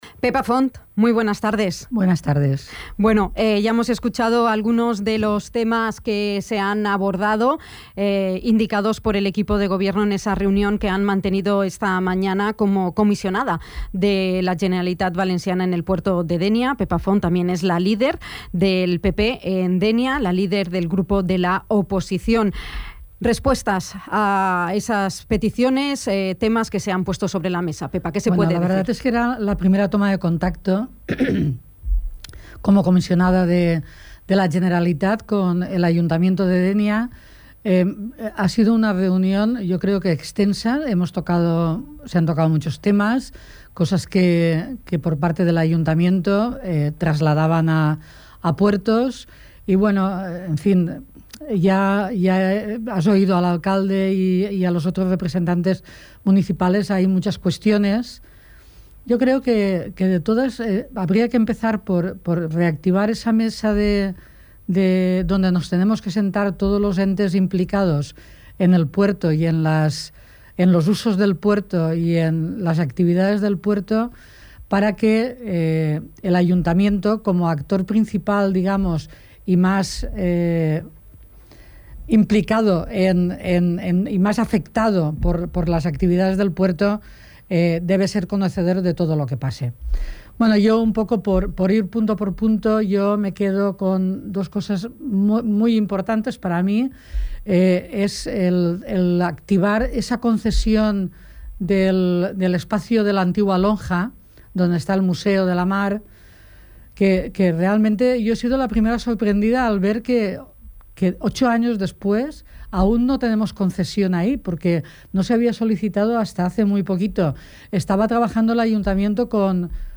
La comisionada de la Generalitat Valenciana en el puerto de Dénia y líder del PP en la ciudad, Pepa Font ha respondido, a través de una entrevista mantenida en Dénia FM, a la comparecencia pública protagonizada por el equipo de gobierno dianense, con las demandas transmitidas en la primera reunión de trabajo que han mantenido, el jueves 4 de enero, ambas partes.
Entrevista-Pepa-Font-balance-23-1.mp3